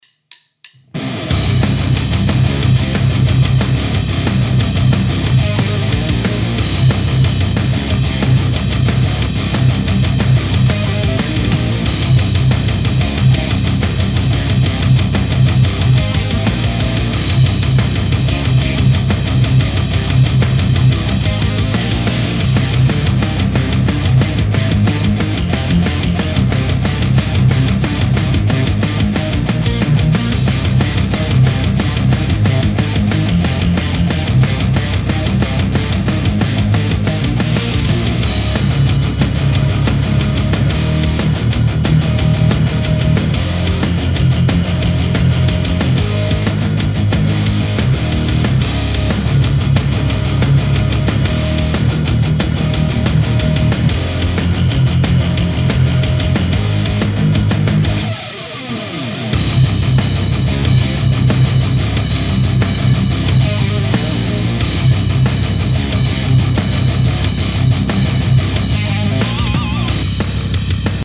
Démonstration :
Boost de 5db (pour que ce soit flagrant) à 3300Hz.
Les 2 grattes sont au mêmes niveau et ont le même son.